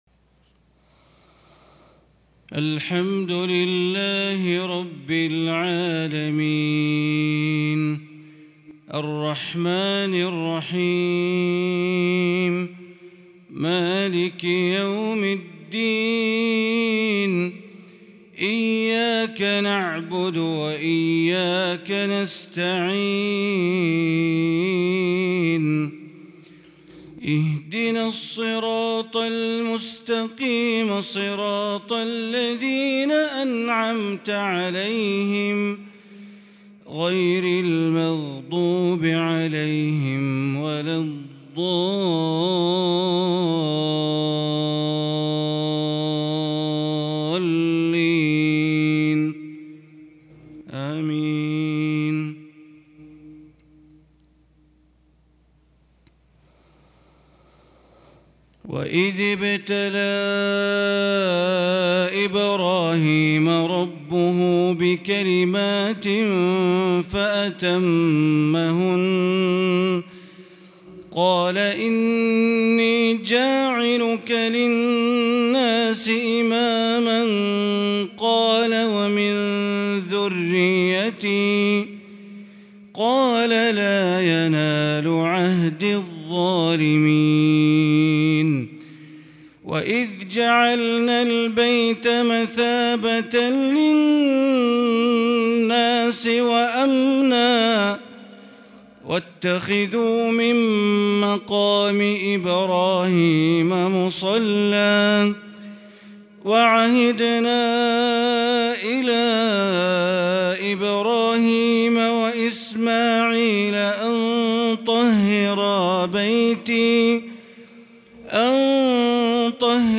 صلاة الفجر للشيخ بندر بليلة 7 ذو الحجة 1442 هـ
تِلَاوَات الْحَرَمَيْن .